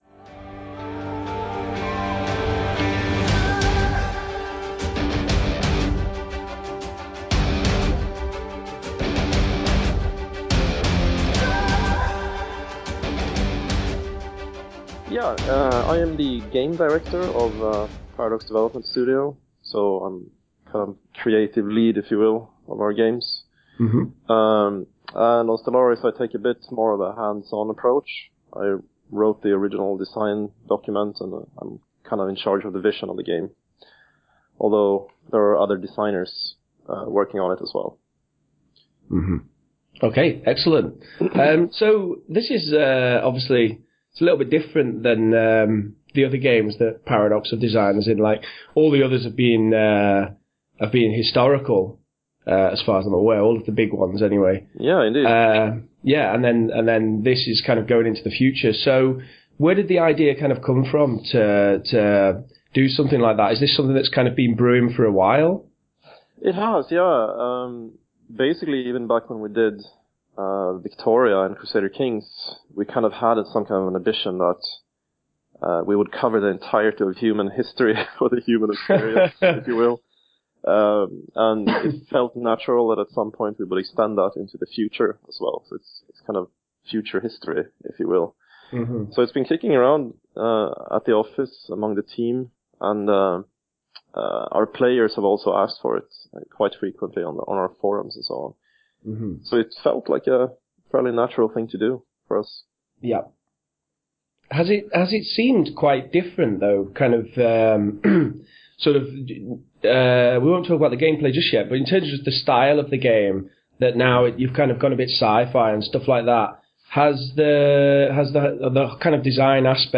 Stellaris Interview
PCI-Stellaris-Interview.mp3